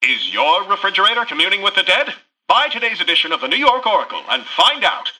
Newscaster_headline_07.mp3